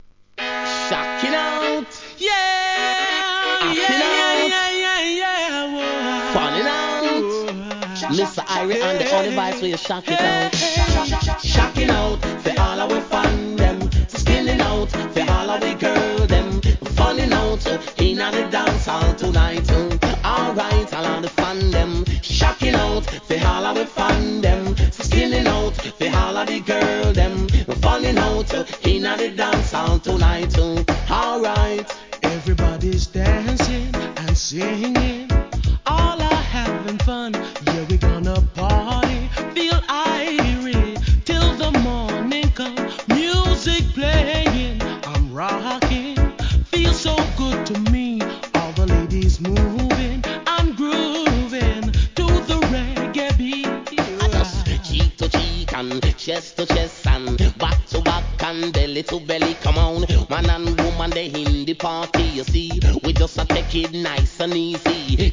REGGAE
爽快なRHYTHMに乗せた人気コンビネーション!!!